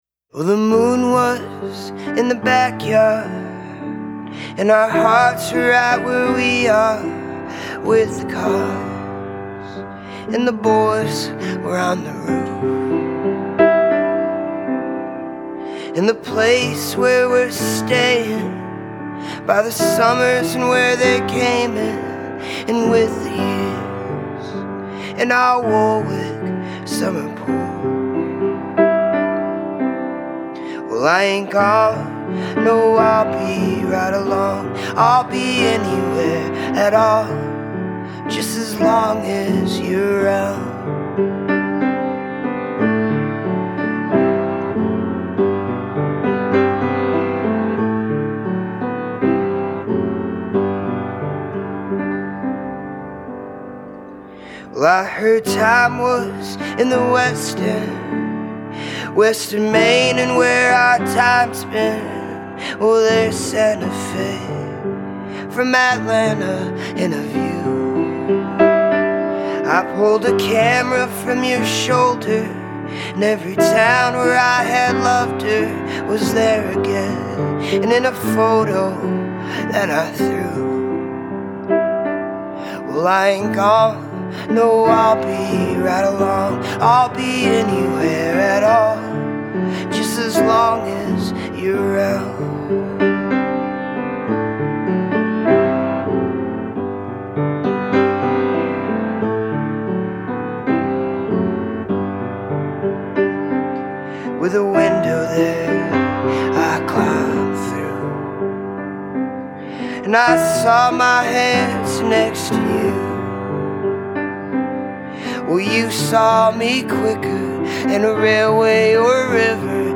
This feels like a very old record to me.
His piercing, simple sweetness totally disarmed me.